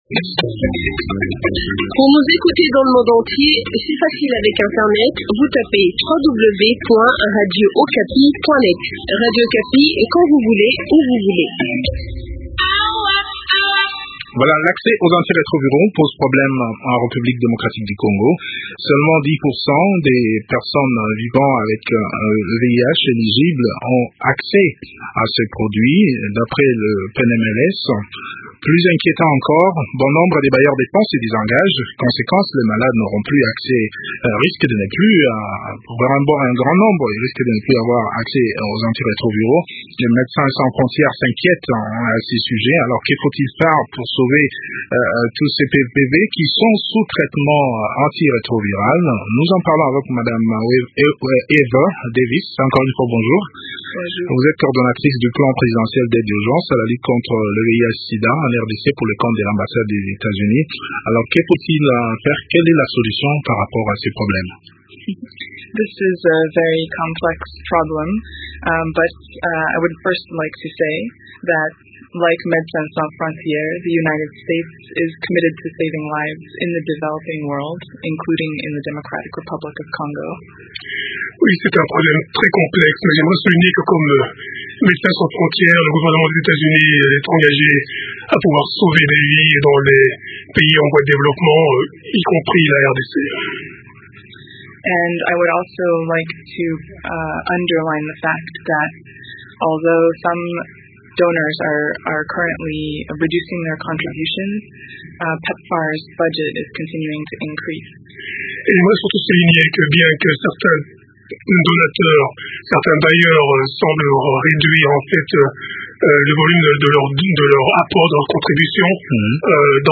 Le point de la situation dans cet entretien